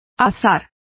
Complete with pronunciation of the translation of hazard.